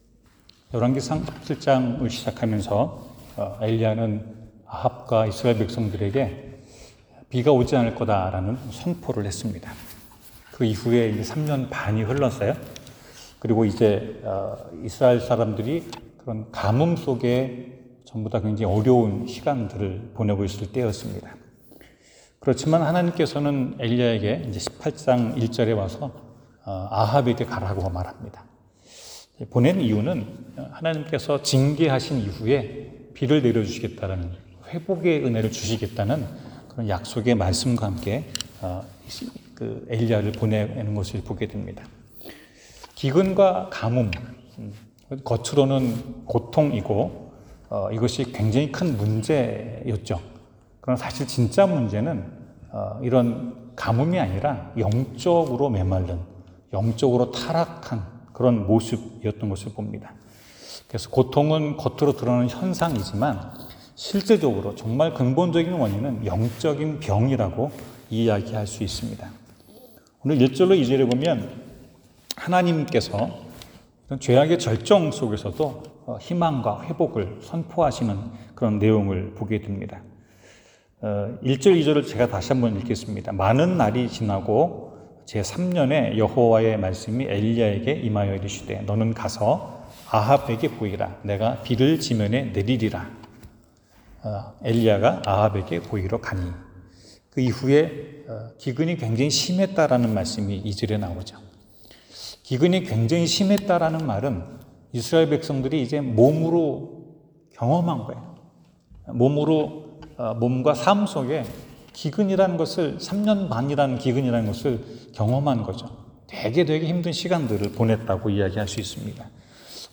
성경: 열왕기상 18:1-18 설교